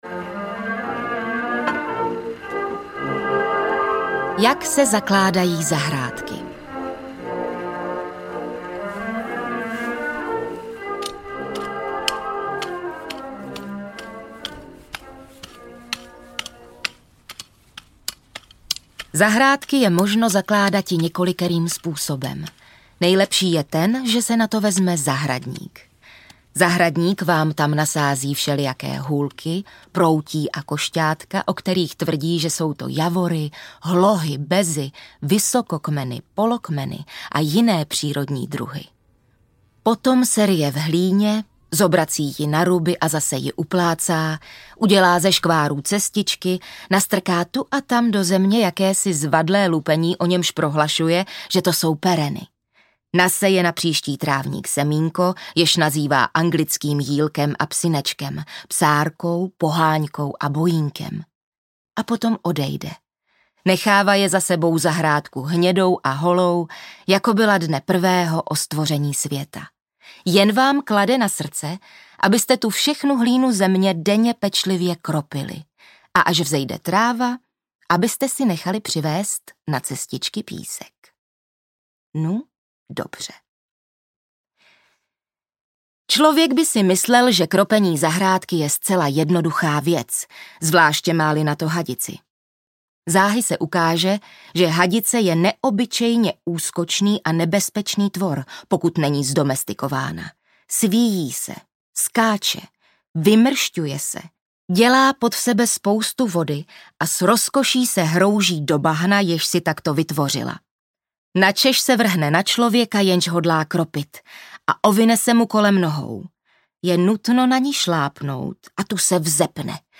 Zahradníkův rok audiokniha
Ukázka z knihy
Zahradníkovým rokem provází příjemný hlas Kateřiny Witerové.
• InterpretKateřina Winterová
zahradnikuv-rok-audiokniha